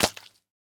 Minecraft Version Minecraft Version snapshot Latest Release | Latest Snapshot snapshot / assets / minecraft / sounds / mob / pufferfish / flop1.ogg Compare With Compare With Latest Release | Latest Snapshot
flop1.ogg